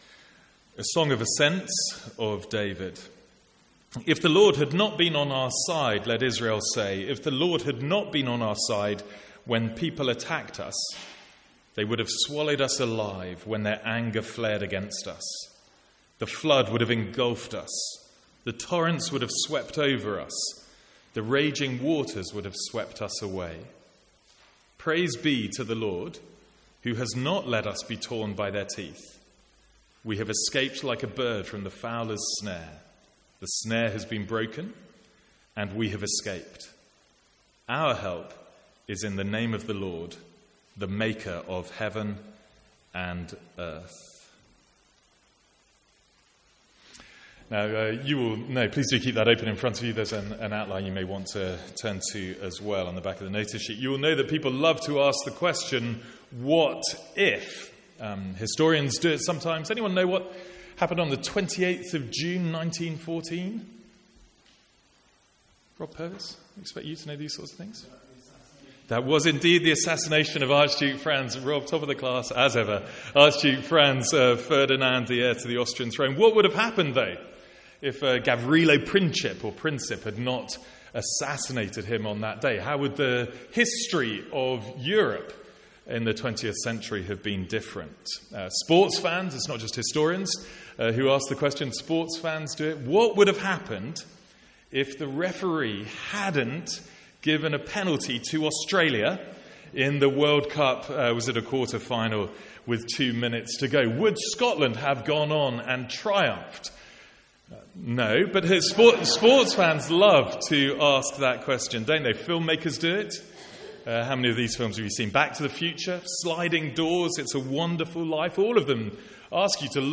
Sermons | St Andrews Free Church
From the Sunday morning series in the Psalms.